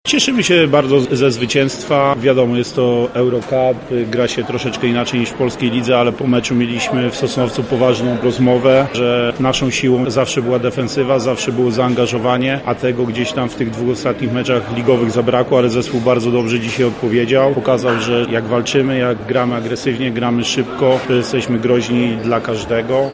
– mówił na konferencji pomeczowej